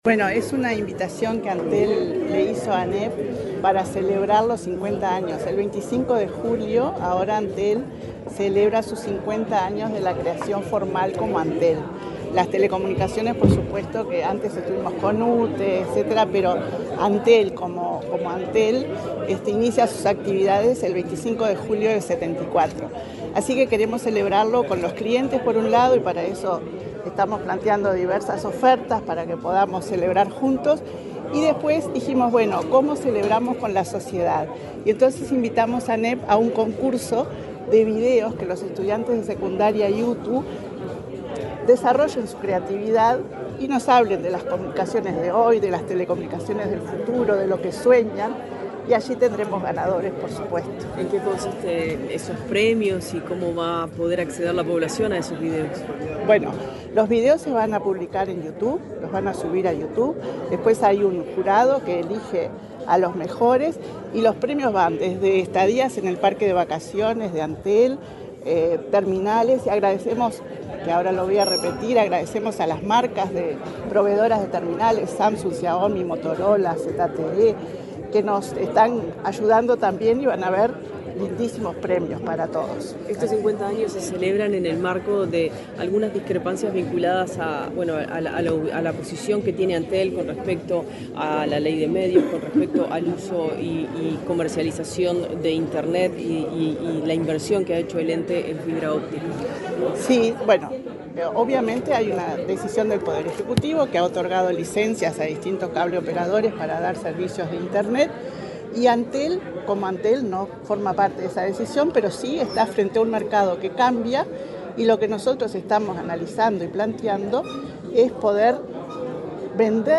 Declaraciones de la presidenta de Antel, Annabela Suburú
Luego, Suburú dialogó con la prensa.